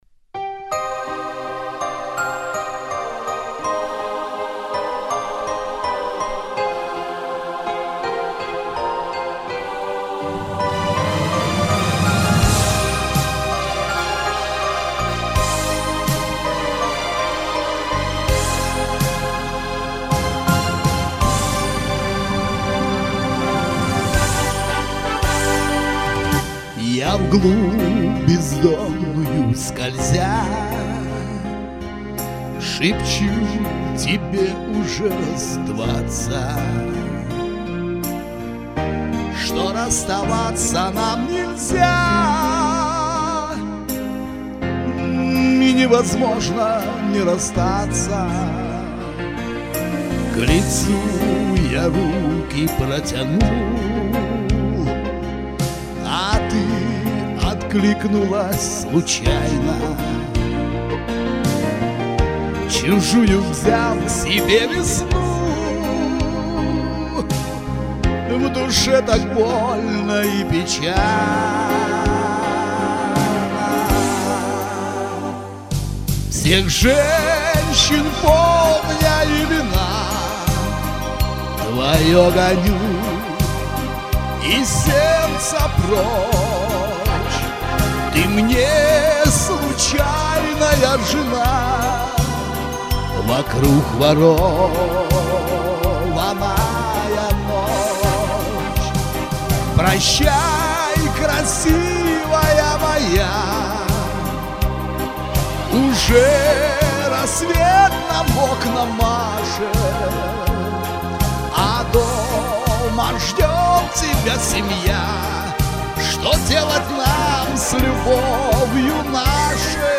Песни разные , но спеты с душой , со страстью.